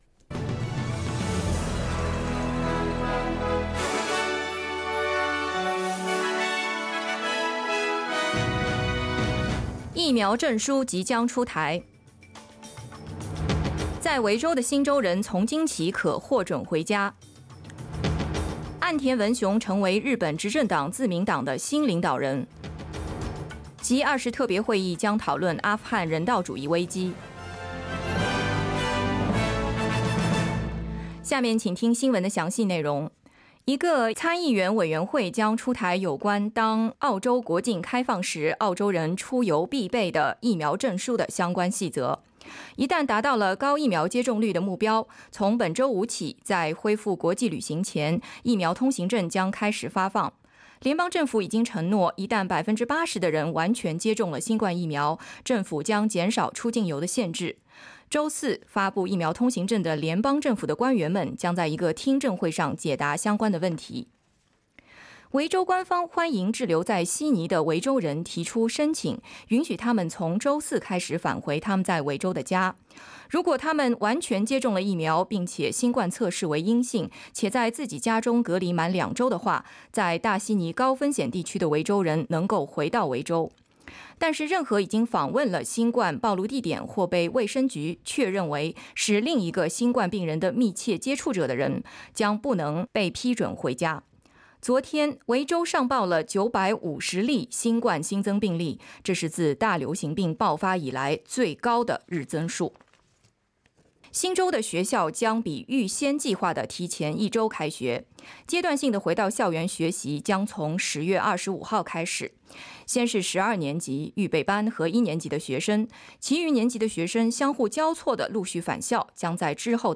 SBS早新聞（2021年9月30日）
SBS Mandarin morning news Source: Getty Images